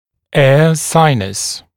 [eə ‘saɪnəs][эа ‘сайнэс]воздушная пазуха